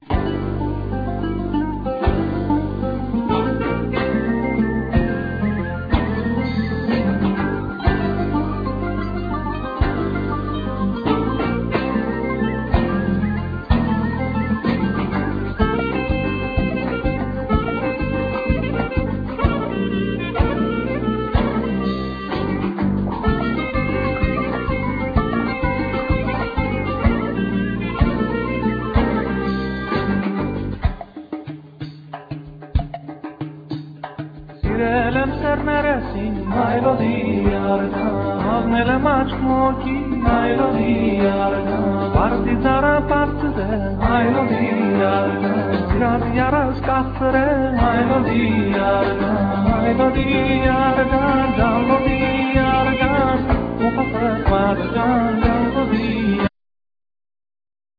Oud
Percussions
Ney
Kanun
Violin
Duduk,Shevi